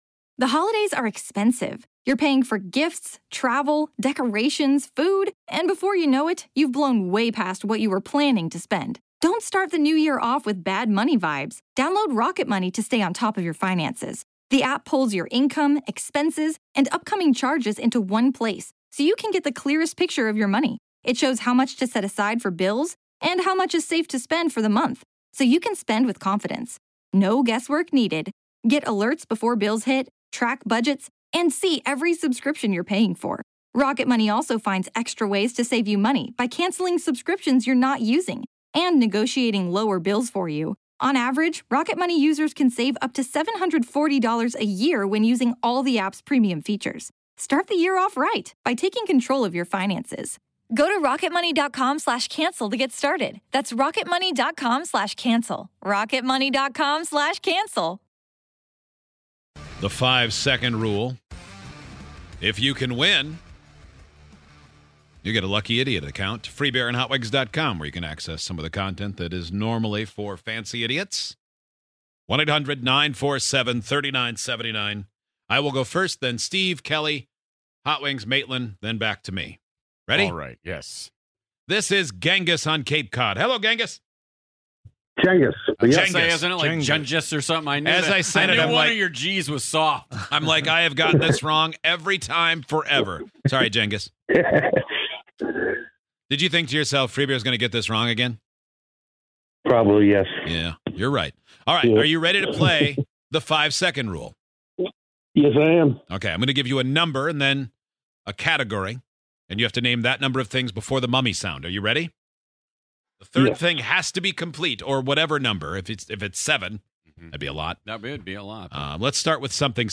On today's show, we asked you to call in to see if you can beat the buzzer in the 5 Second Rule. Think you're quick enough on your feet to beat the buzzer?